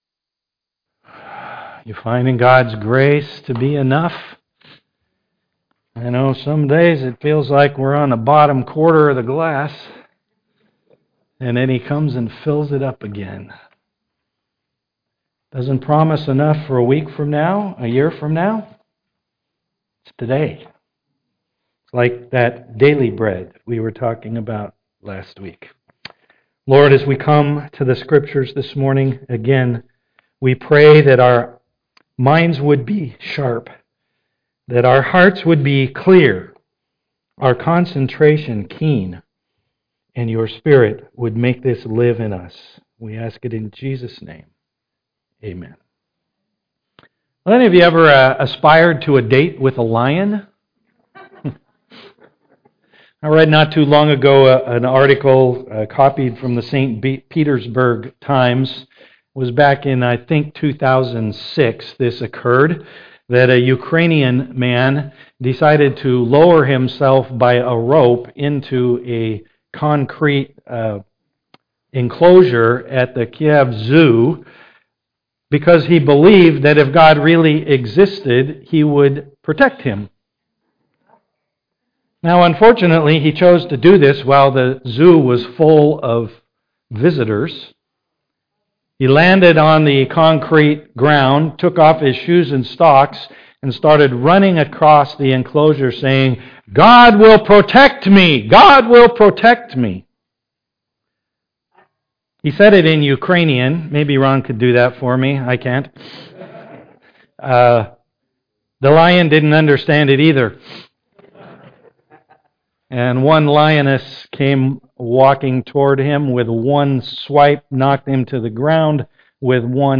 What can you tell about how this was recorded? Service Type: am worship NOTE: We are back meeting inside and messages will be recorded live and posted as early on Sunday afternoon as possible.